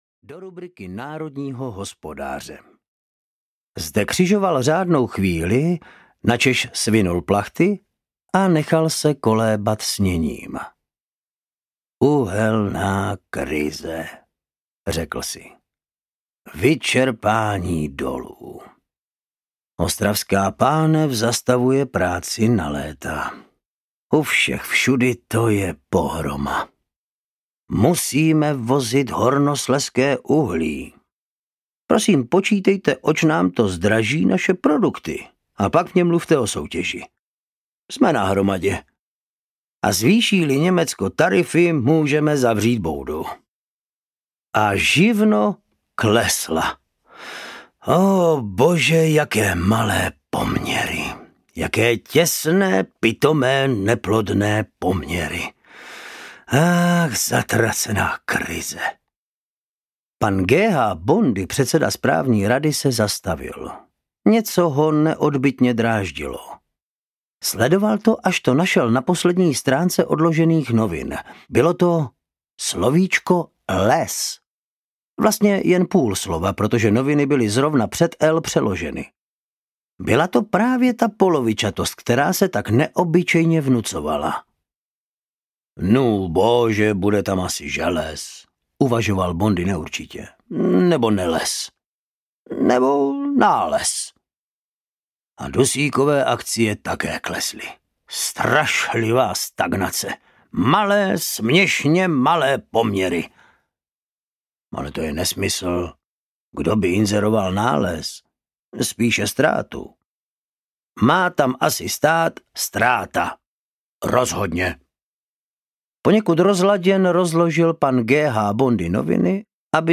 Továrna na absolutno audiokniha
Ukázka z knihy
tovarna-na-absolutno-audiokniha